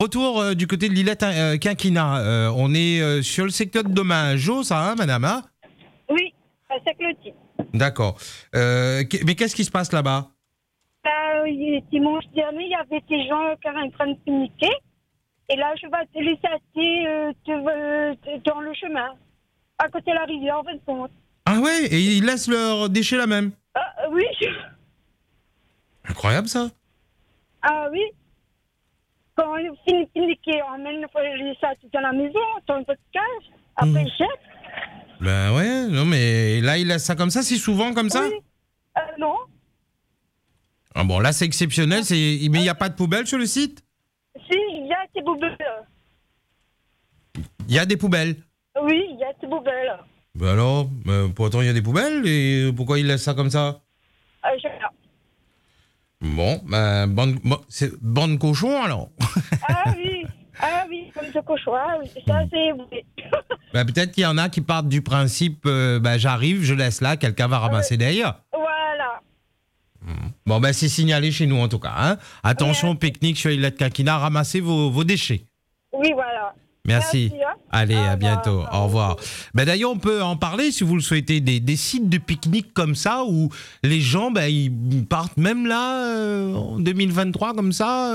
Ce dimanche 5 février des personnes auraient pic niqué à L’ilet quinquina et auraient jeté leurs déchets à côté de la rivière, malgré la présence de poubelles sur le site. Un manque de civisme qui a particulièrement déçu notre auditrice, comme vous allez pouvoir l’entendre dans ce podcast :